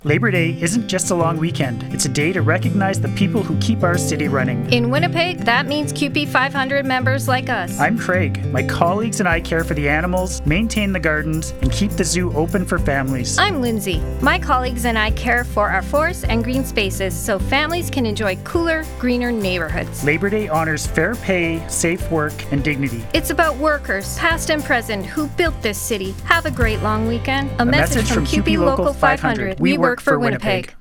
Labour Day Radio Ad